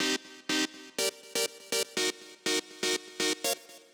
AIR Dee Stab Riff C.wav